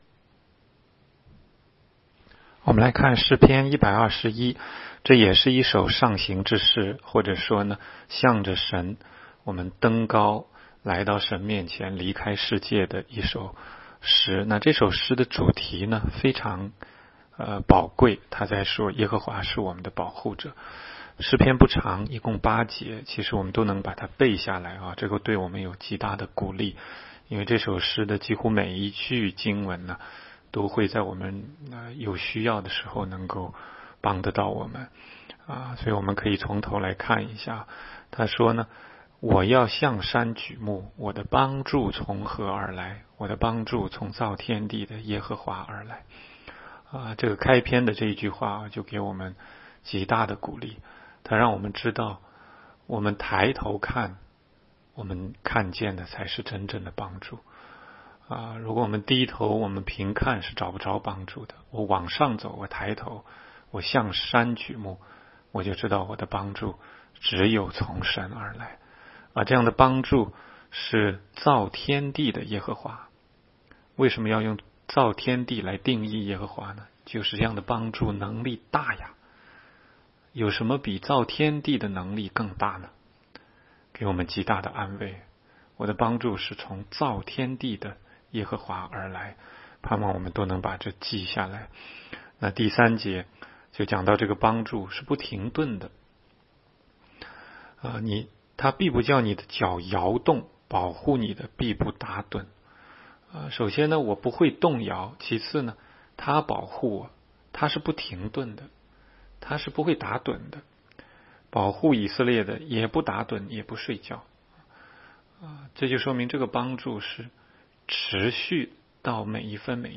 16街讲道录音 - 每日读经 -《 诗篇》121章